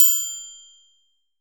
Perc (Triangle).wav